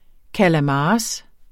Udtale [ kalaˈmɑːɑs ]